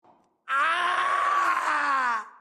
Dark Souls/Bloodborne death SFX, for the meme.